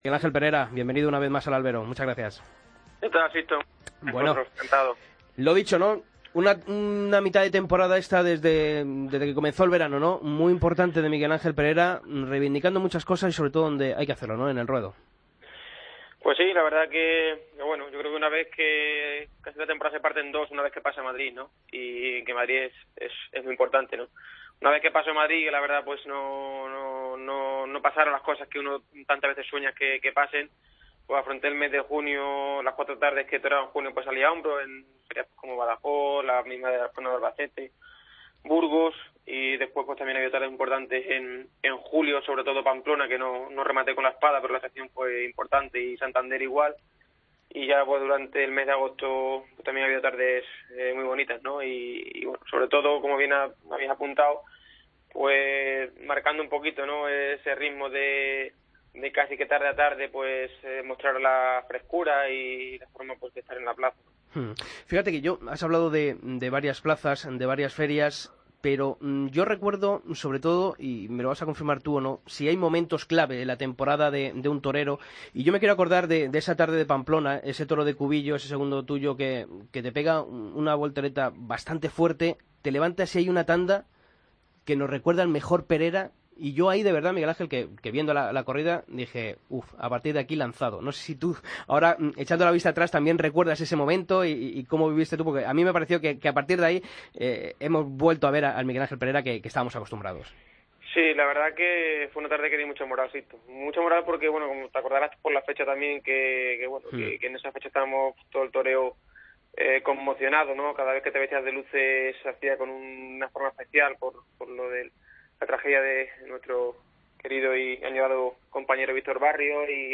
Escucha la entrevista completa a Miguel Ángel Perera en El Albero